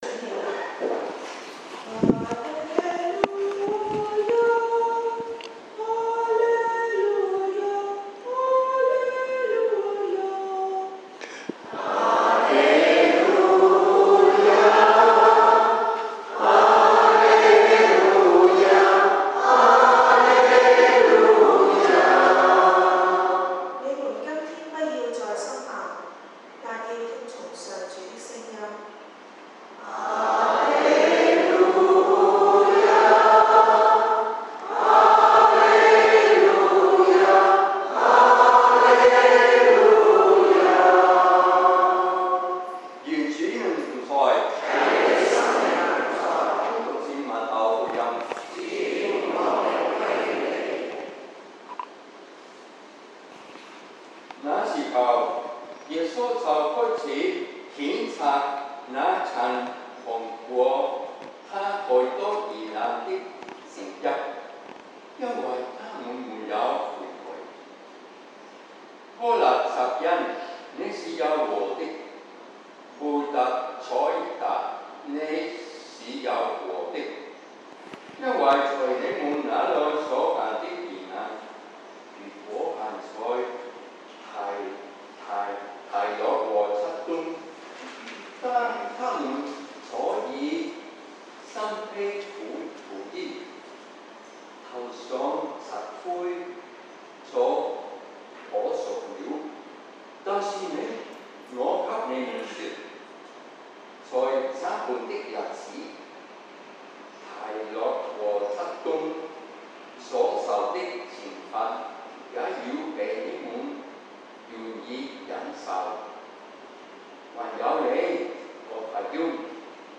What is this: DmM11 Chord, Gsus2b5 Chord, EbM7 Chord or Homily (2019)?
Homily (2019)